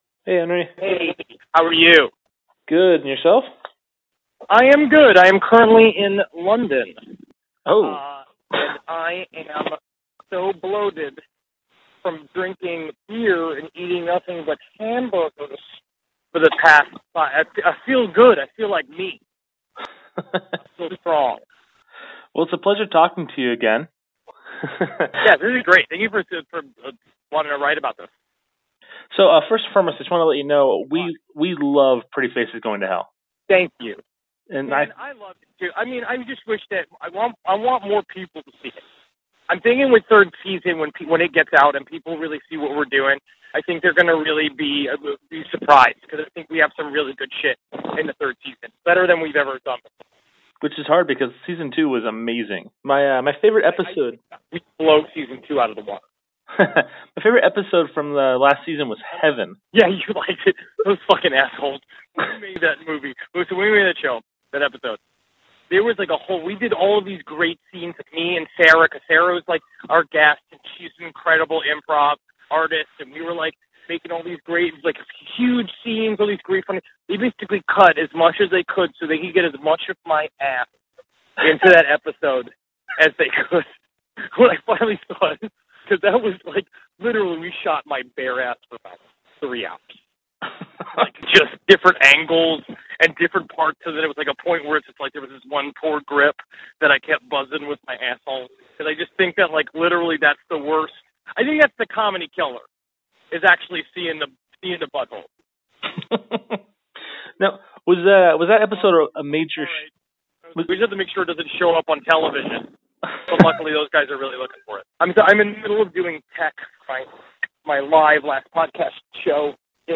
Thanks to it’s return, Adult Swim let us sit down and talk to the main actor Henry Zebrowski. This being our second interview we have done with him, it allowed us to really get into some fun and interesting areas.